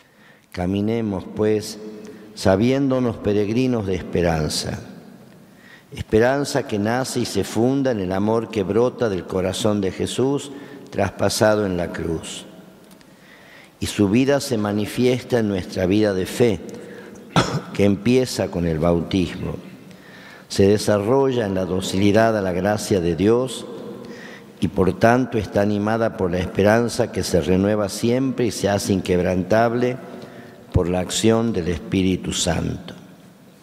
La ceremonia fue presidida por monseñor Mario Cargnello en la Catedral Basílica de Salta.